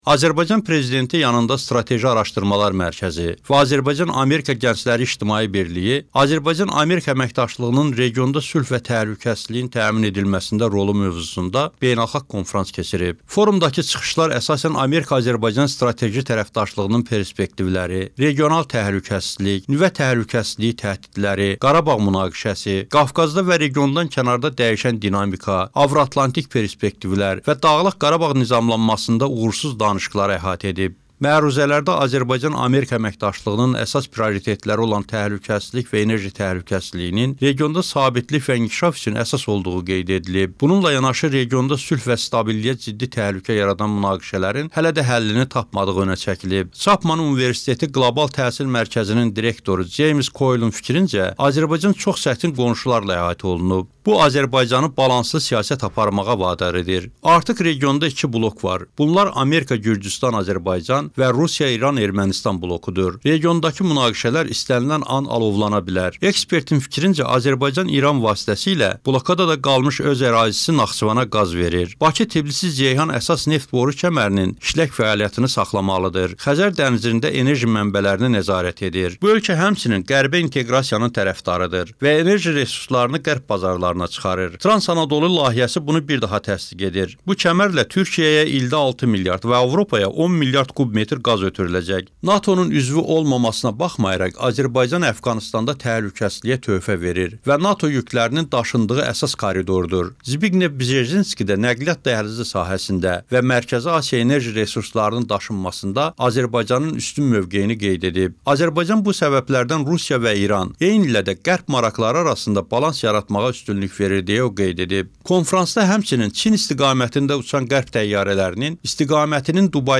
Oktyabrın 19-da Bakıda Azərbaycan Prezidenti yanında Strateji Araşdırmalar Mərkəzi və Azərbaycan-Amerika Gəncləri İctimai Birliyi “Azərbaycan-ABŞ əməkdaşlığının regionda sülh və təhlükəsizliyin təmin edilməsində rolu” mövzusunda beynəlxalq konfrans keçirilib.
"Amerika-Azərbaycan münasibətləri və Qarabağ" reportajı